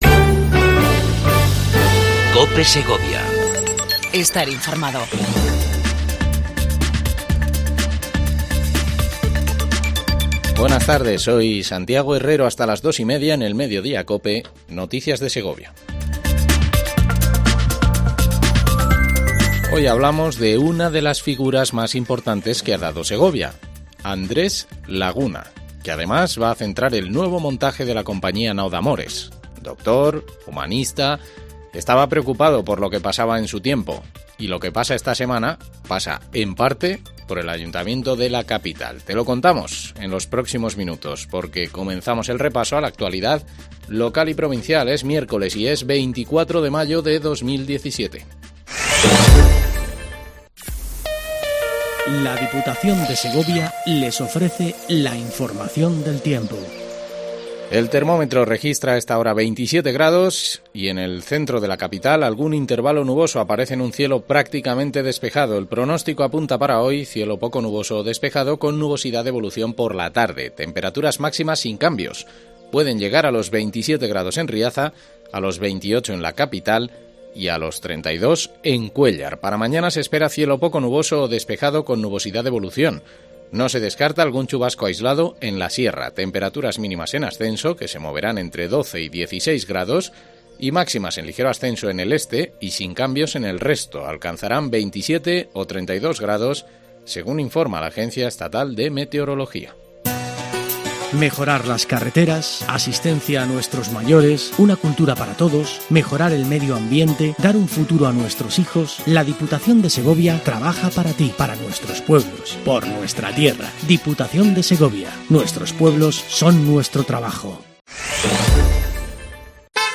INFORMATIVO MEDIODIA COPE EN SEGOVIA 24 05 17